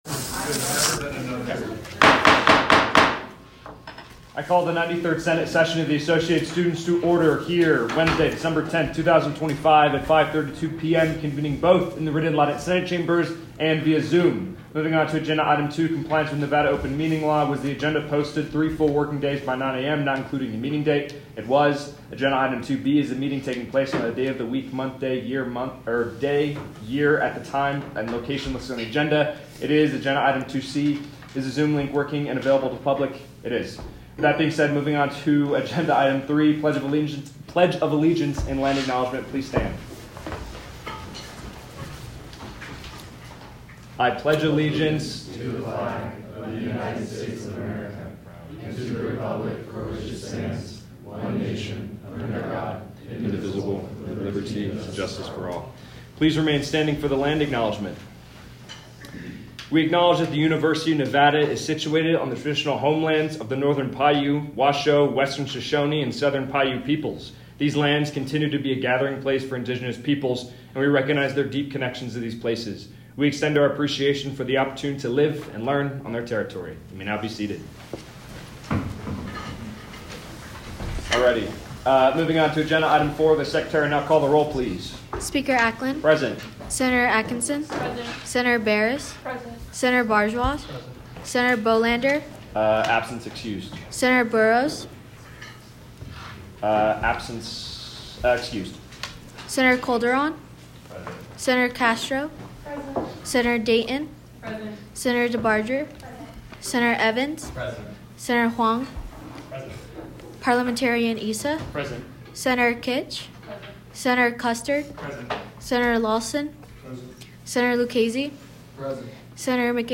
Meeting Type : Senate
Location : Rita Laden Senate Chambers